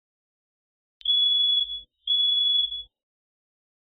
Free UI/UX sound effect: Alarm Beep.
Alarm Beep
101_alarm_beep.mp3